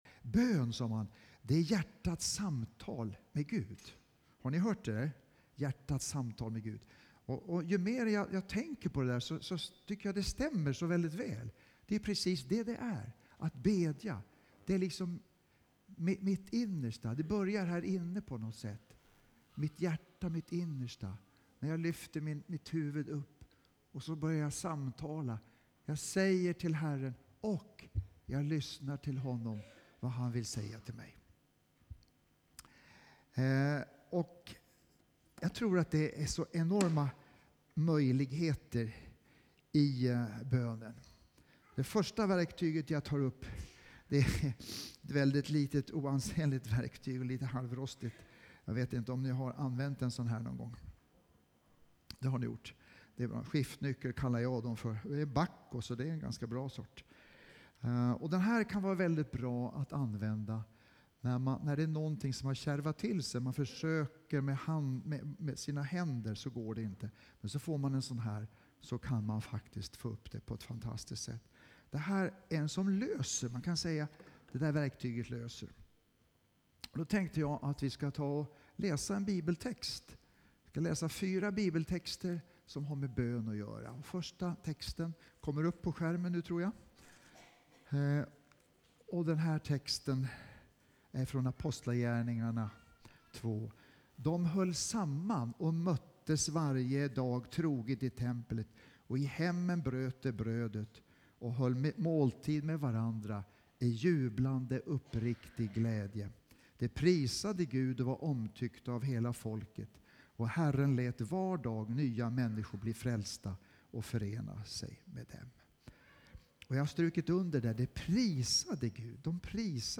Predikan